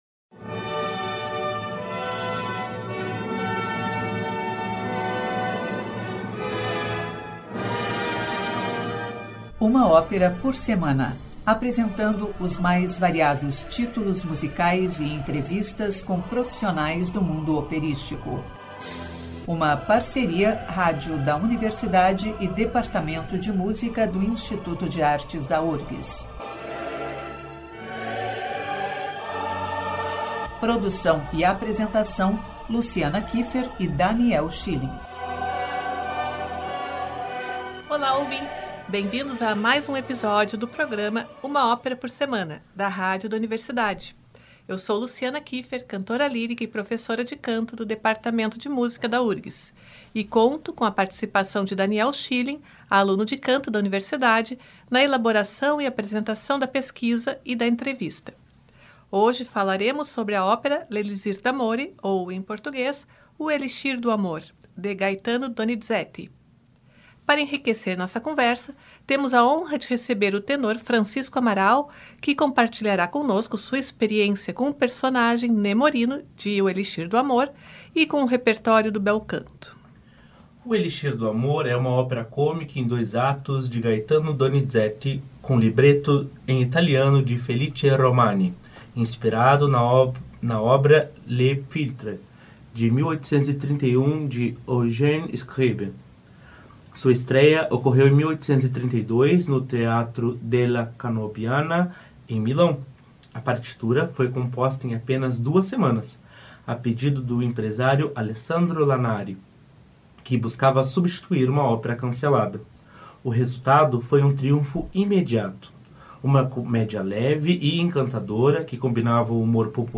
Ópera